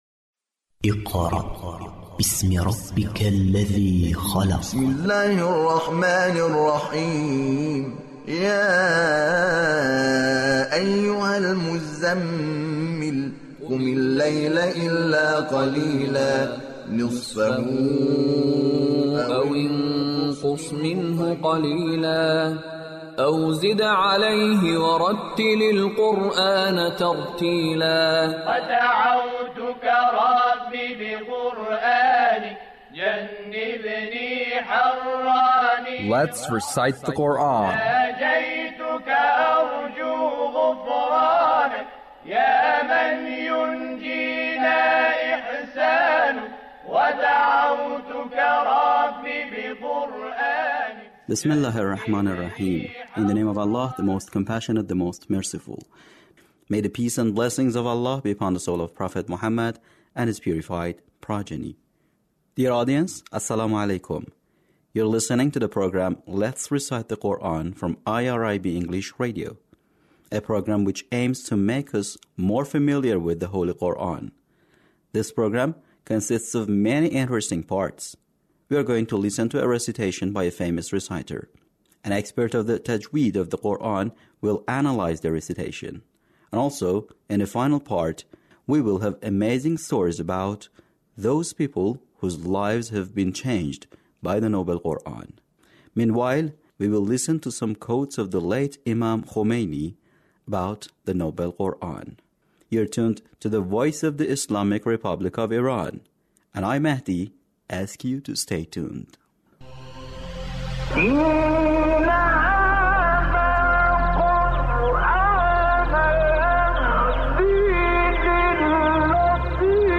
Recitation of surah al-Kawthar - Attractiveness of the Noble Quran